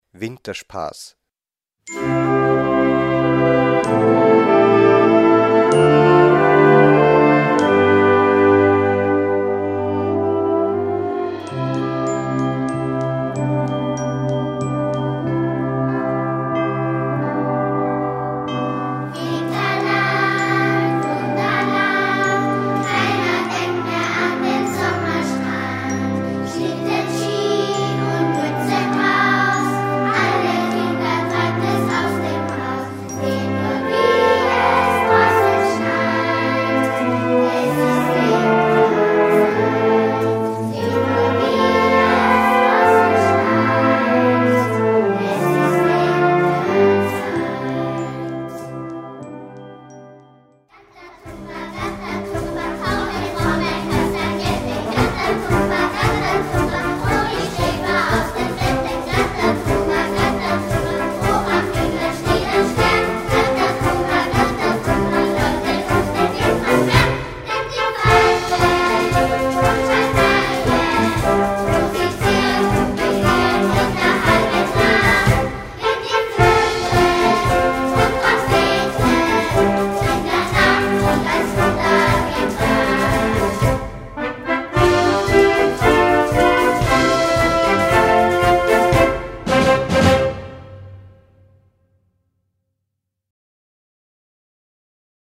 Mit Kinderchor.
Kinderweihnachtspotpourri Schwierigkeit
Blasorchester Tonprobe